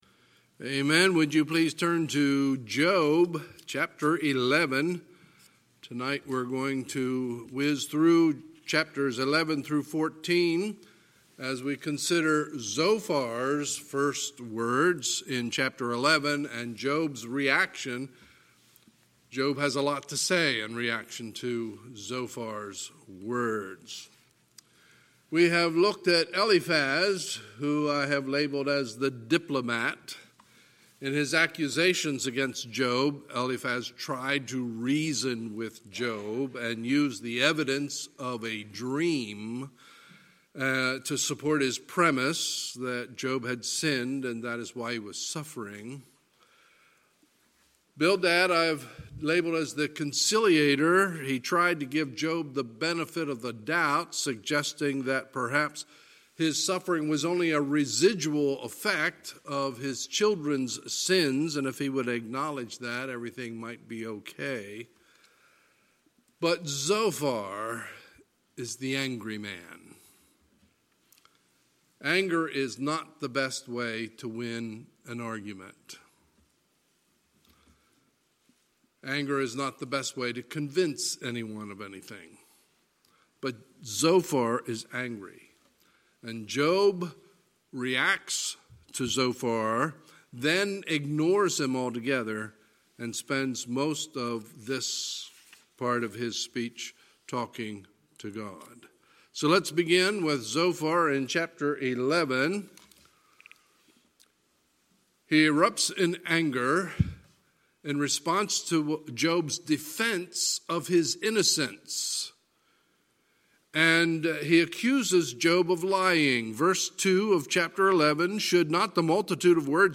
Sunday, February 9, 2020 – Sunday Evening Service
Sermons